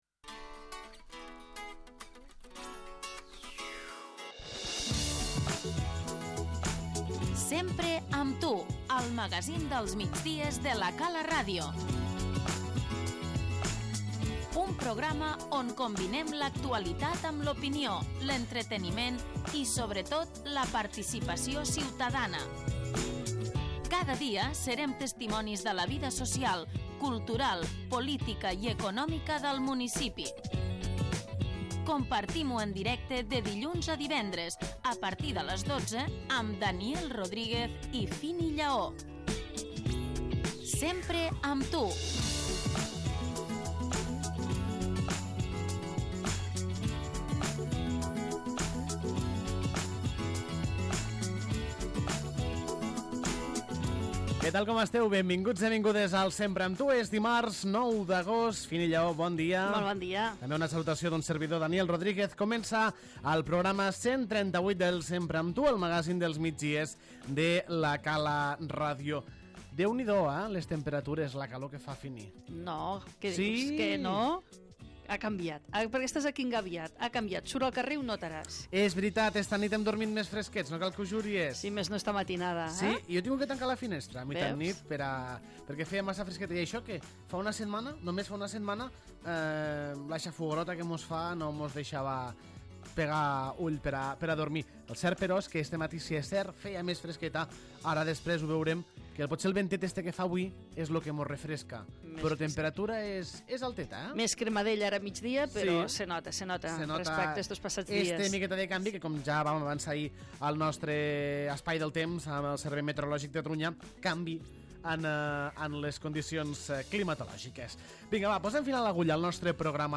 Avui dimarts dia 9 d'agost de 2016, en el magazín dels migdies n. 138 de La Cala RTV, hem tractat aquestes seccions:
L'ENTREVISTA